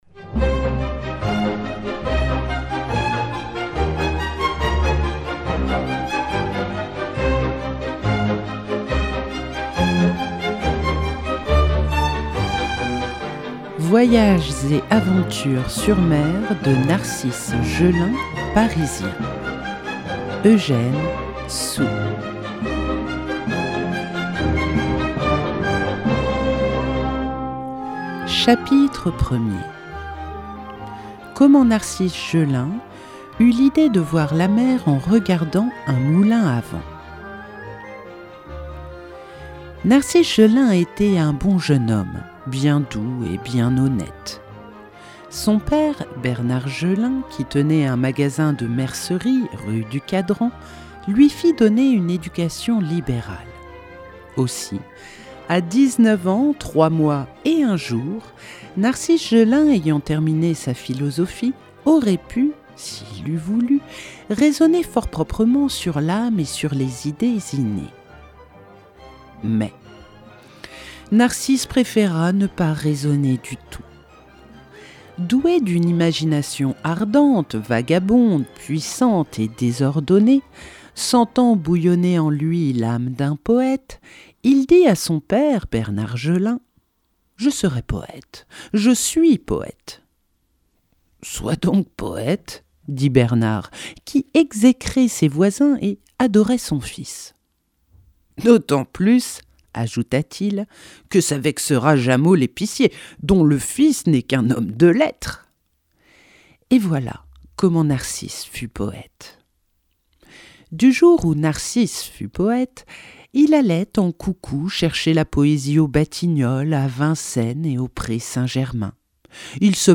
Nouvelle (38:19)